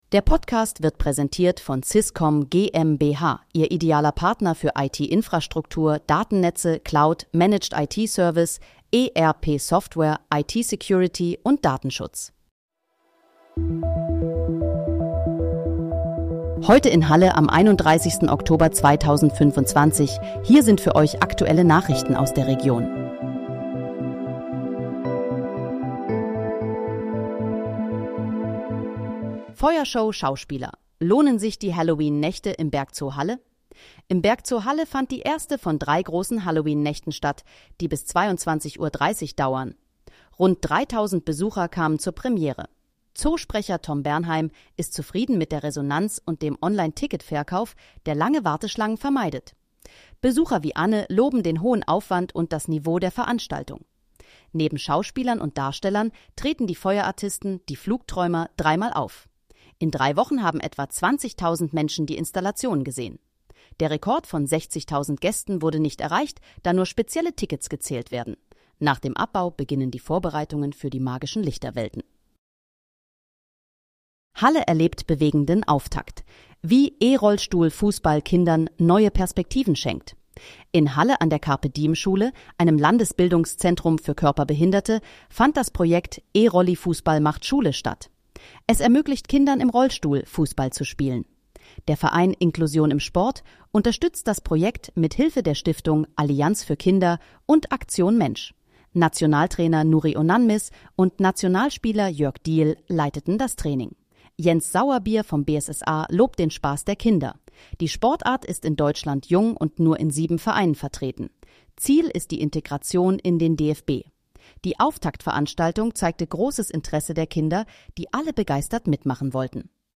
Heute in, Halle: Aktuelle Nachrichten vom 31.10.2025, erstellt mit KI-Unterstützung
Nachrichten